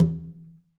Conga-HitN_v2_rr1_Sum.wav